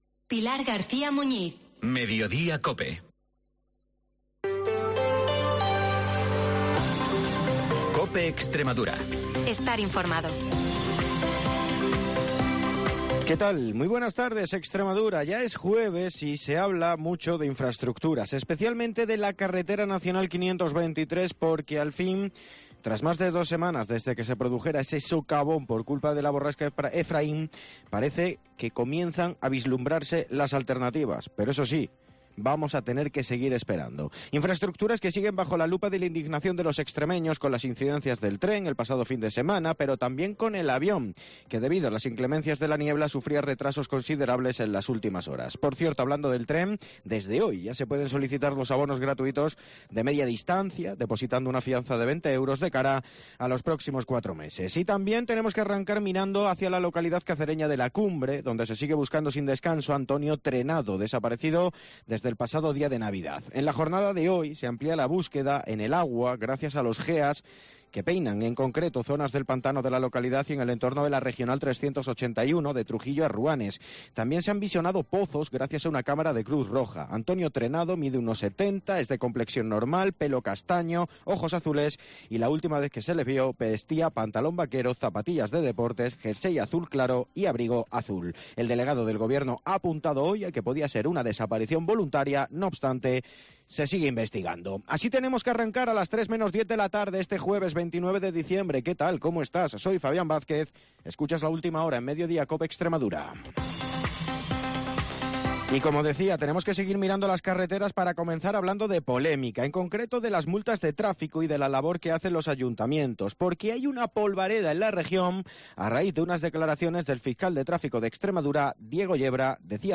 te cuenta la última hora de la región de lunes a viernes en Mediodía COPE